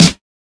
• '00s Piercing Snare Drum Sound F Key 51.wav
Royality free snare drum sample tuned to the F note. Loudest frequency: 2470Hz
00s-piercing-snare-drum-sound-f-key-51-I2W.wav